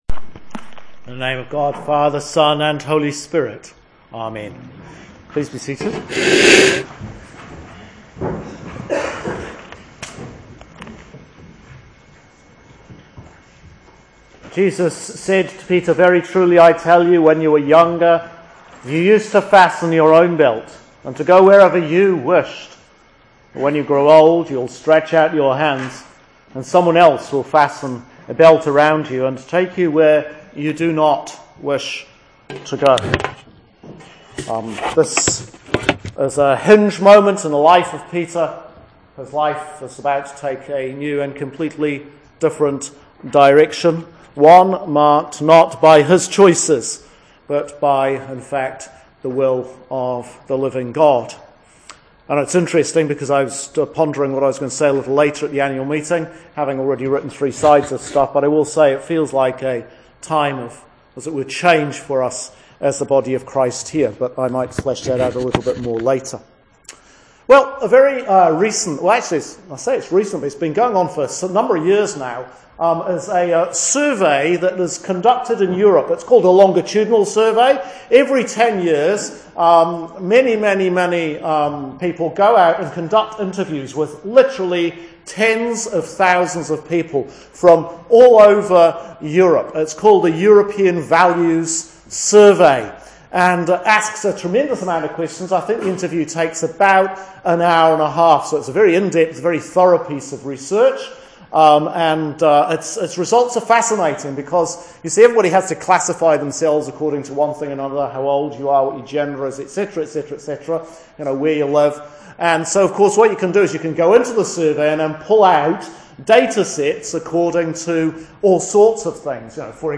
A couple of recent sermons